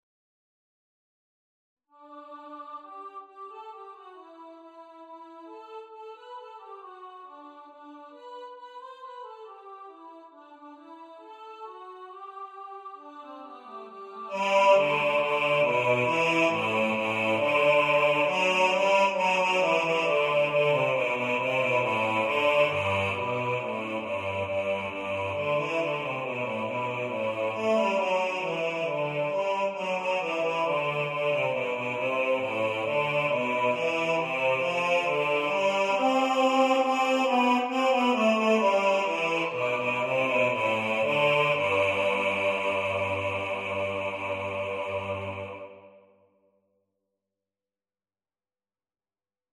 We-Wish-You-A-Merry-Christmas-Bass.mp3